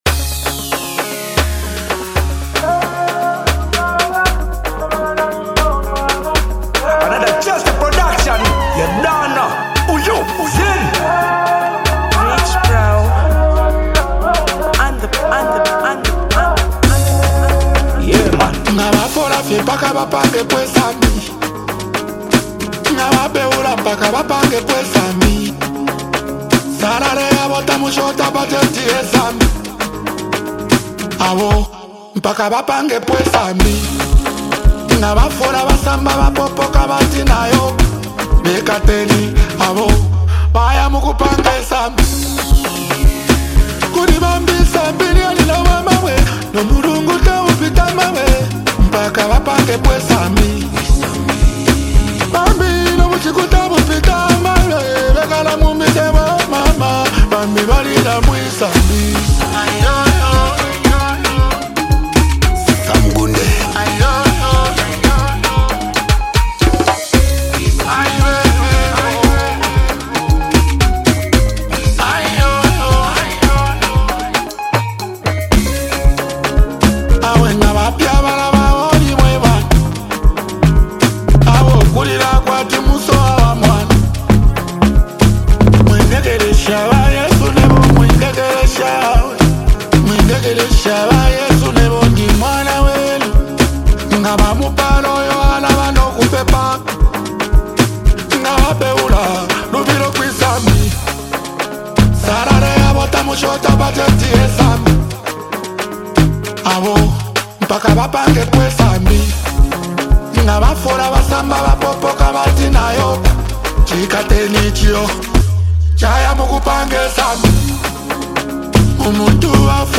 delivers heartfelt verses filled with raw emotion